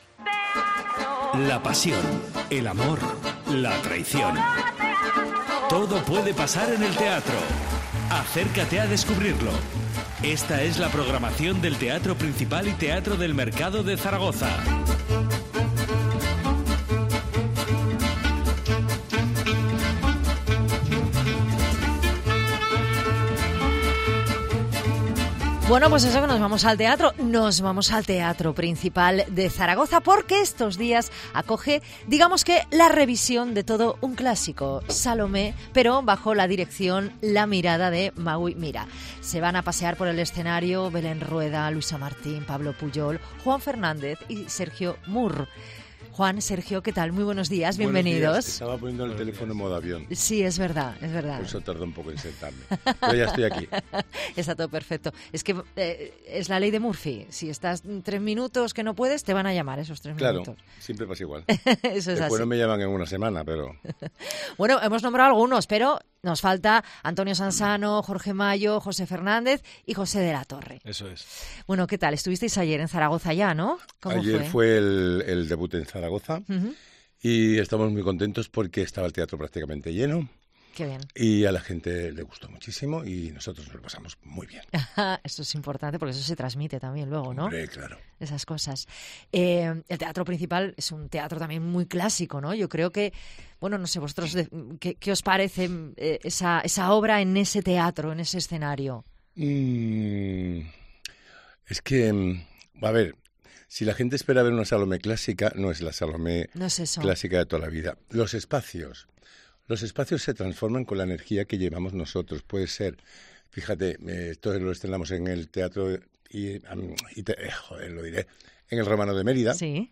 Entrevista a los actores Juan Fernández y Sergio Mur sobre la obra teatral 'Salomé'